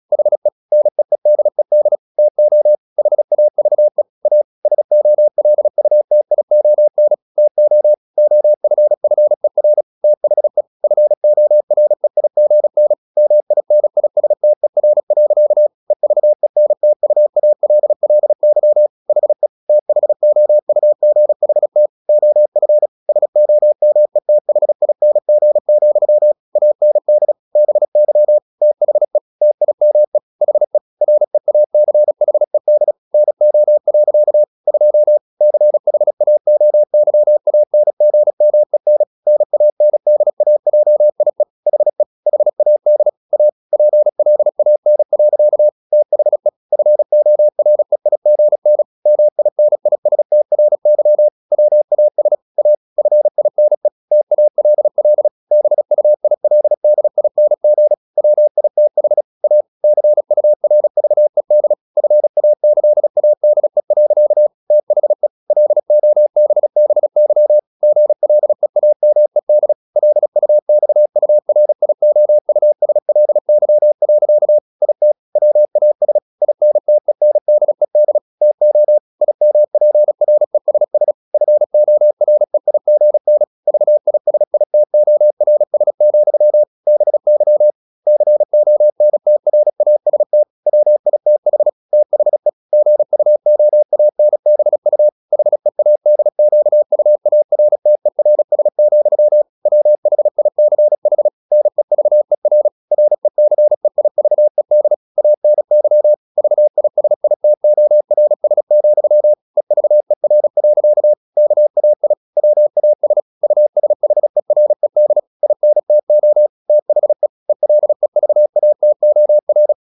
Never 36wpm | CW med Gnister